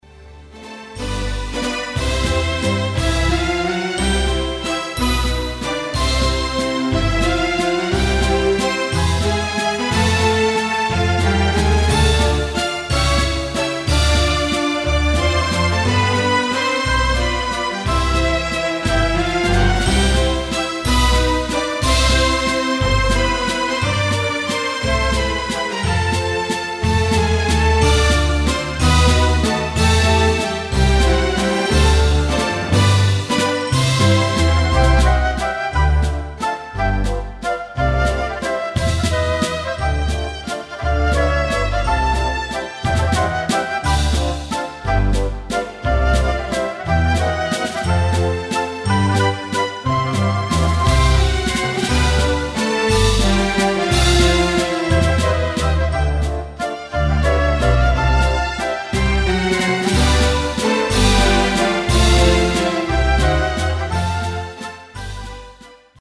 Valzer viennese
Tastiere / Orch.